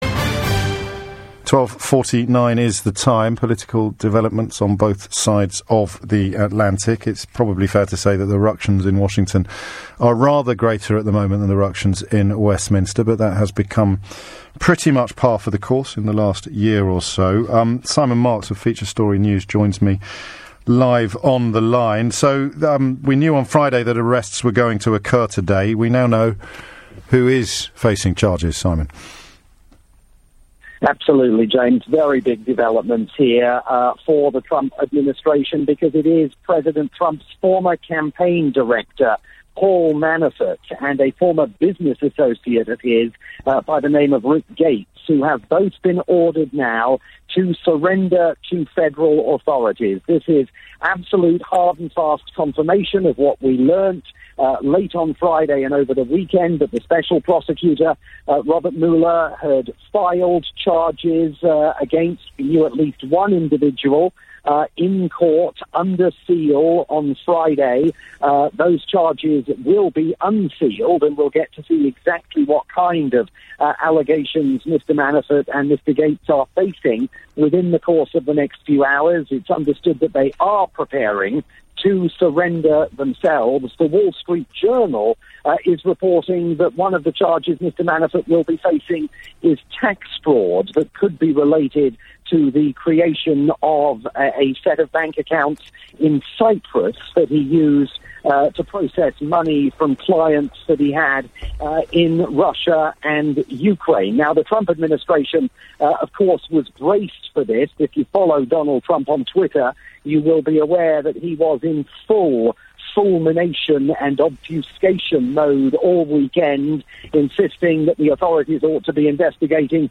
breaking news report